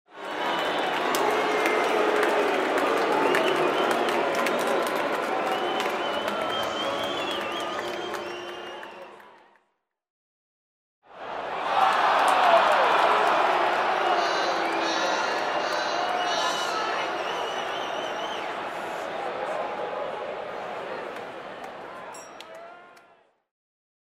Звуки недовольной толпы
Болельщики на стадионе возмущенно кричат